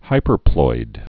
(hīpər-ploid)